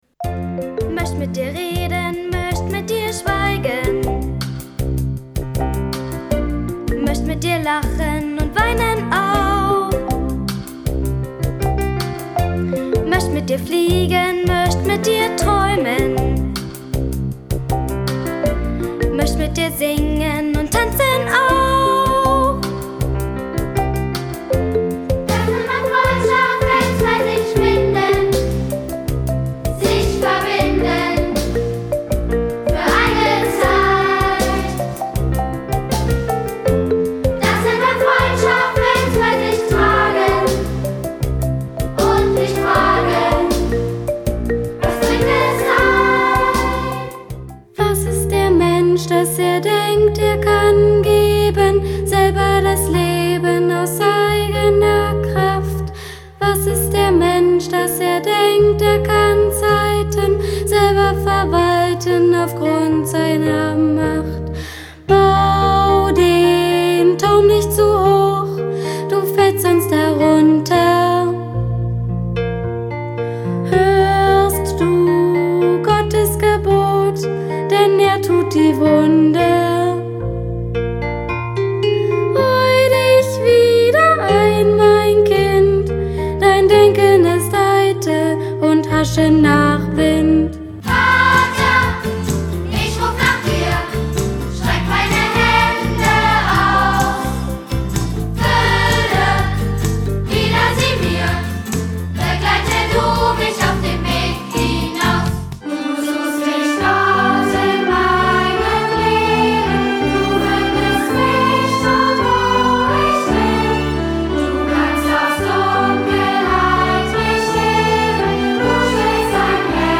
Gemeindelied